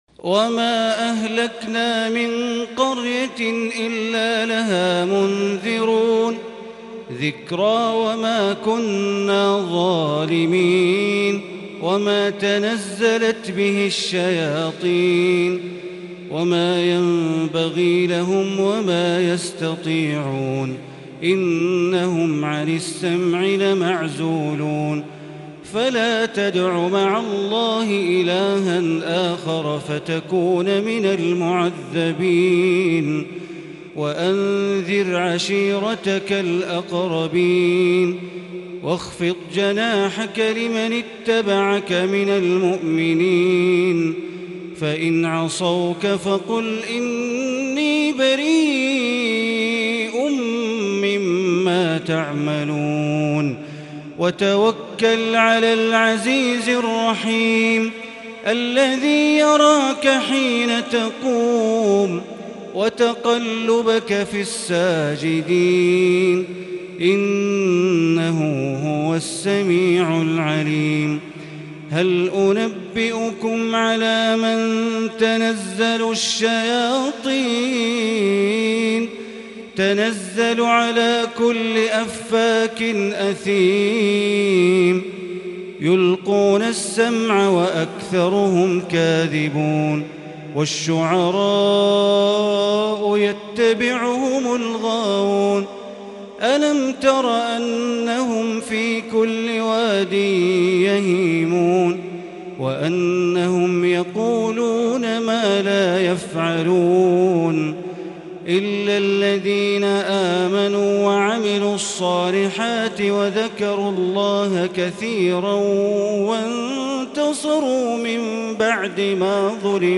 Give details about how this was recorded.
Tarawih prayer on the night of the twenty-third of Ramadan for the year 1441 recited from Surat AshShu'ara' verse 208 to Surat AnNaml verse 58 > 1441 > Taraweeh - Bandar Baleela Recitations